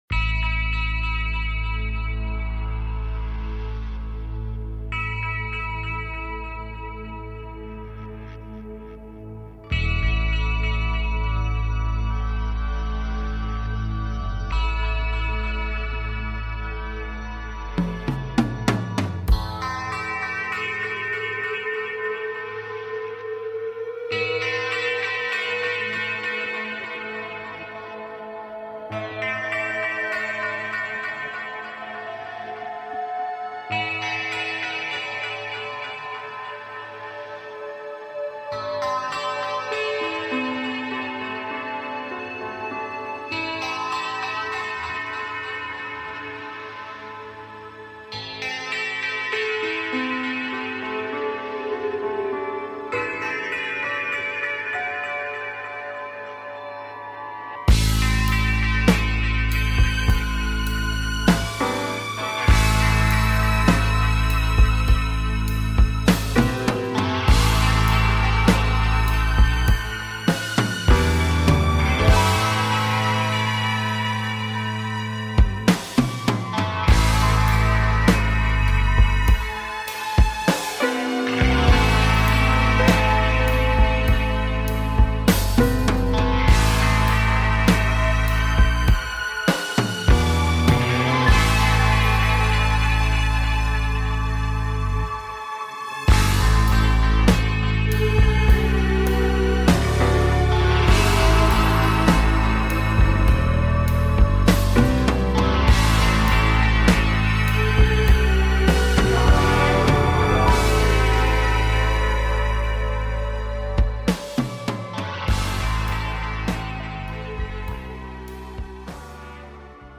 Talk Show Episode
Show on Homesteading and taking caller questions